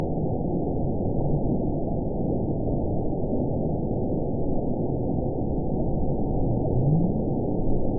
event 917796 date 04/16/23 time 23:21:24 GMT (2 years, 1 month ago) score 9.30 location TSS-AB03 detected by nrw target species NRW annotations +NRW Spectrogram: Frequency (kHz) vs. Time (s) audio not available .wav